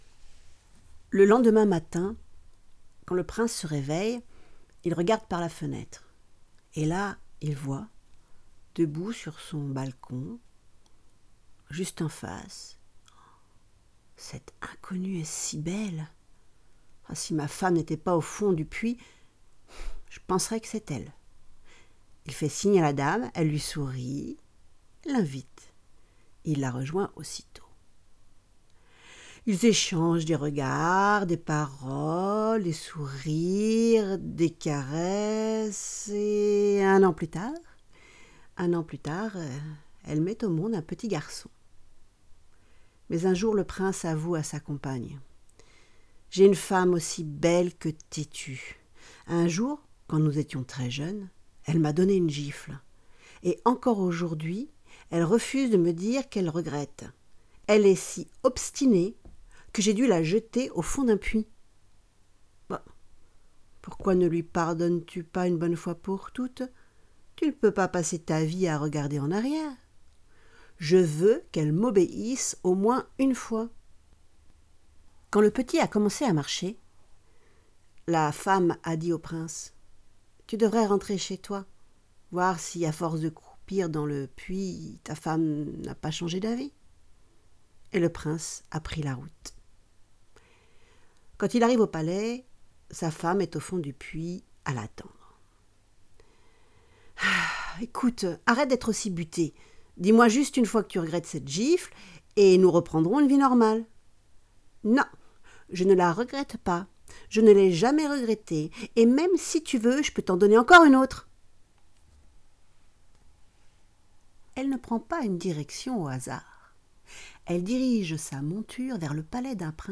Spectacle de contes